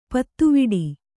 ♪ pattuviḍi